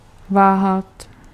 Ääntäminen
IPA: [ba.lɑ̃.se]